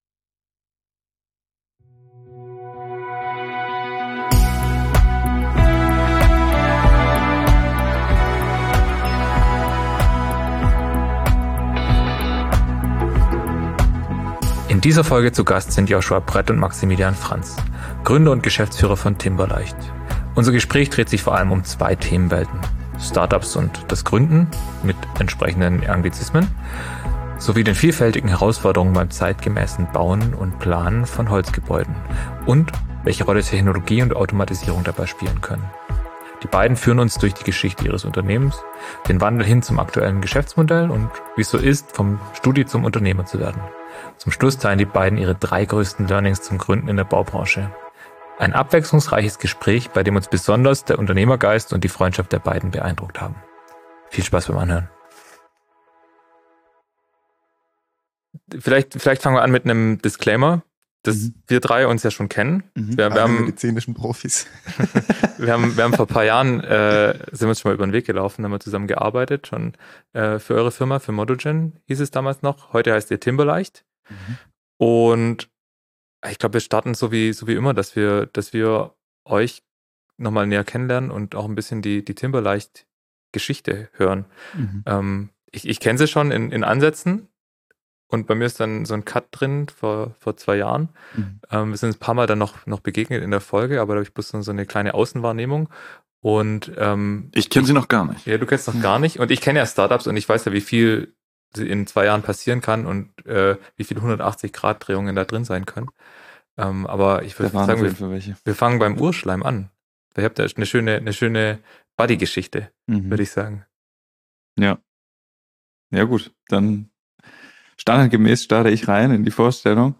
Ein abwechslungsreiches Gespräch bei dem uns besonders der Unternehmergeist und die Freundschaft der beiden beeindruckt haben.